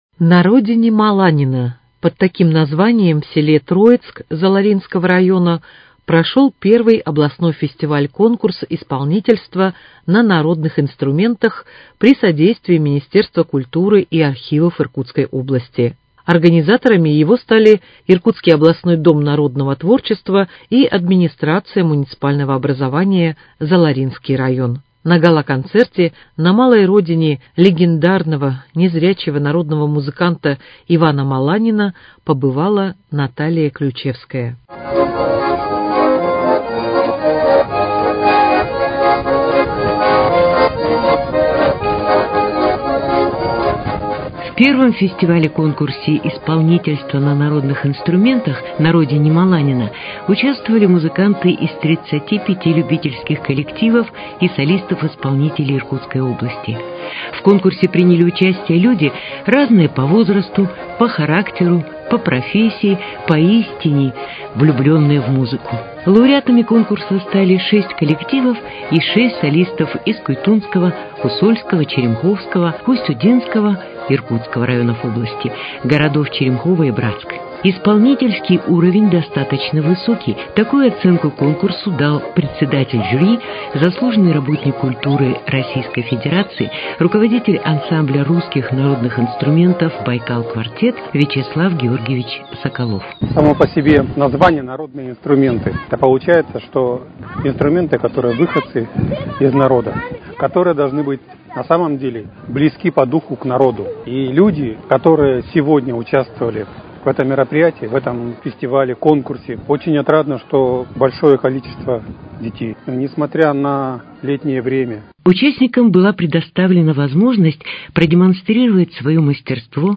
Репортаж о фестивале-конкурсе памяти слепого баяниста «На родине Маланина»